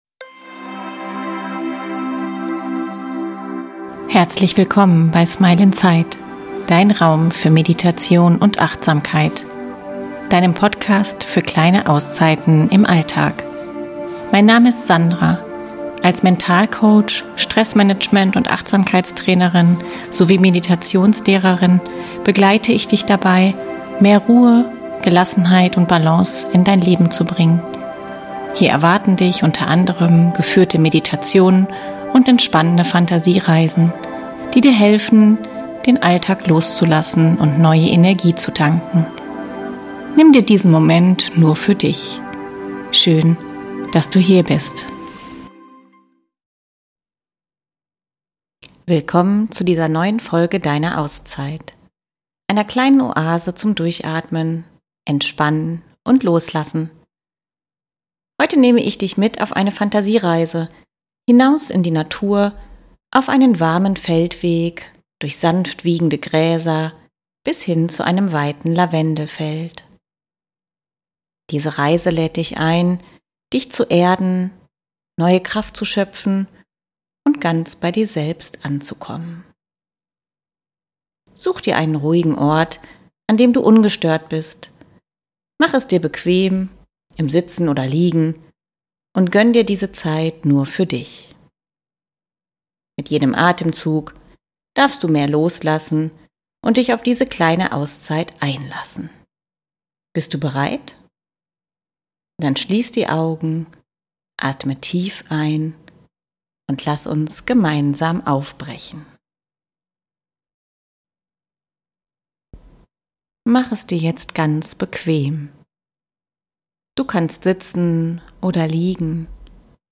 Wenn dir diese Meditation gefällt, freue ich mich, wenn du auch in meine anderen Folgen hineinhörst.